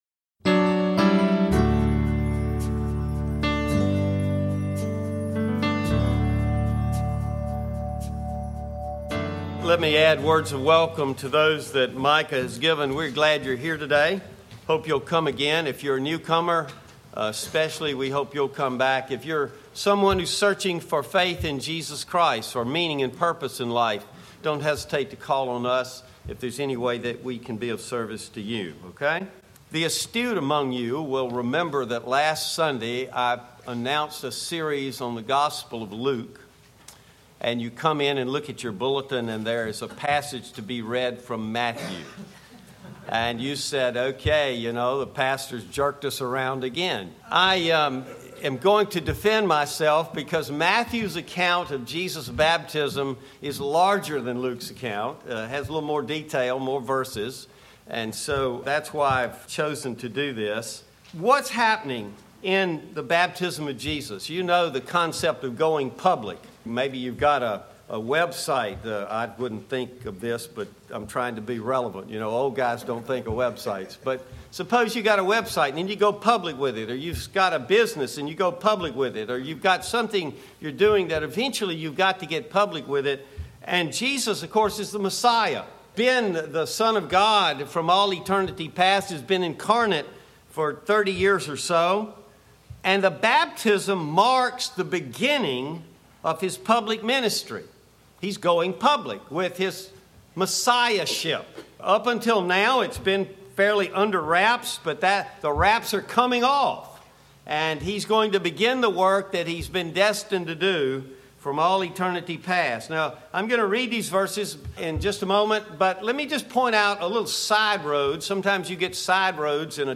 Bible Text: Matthew 3:13-17 | Preacher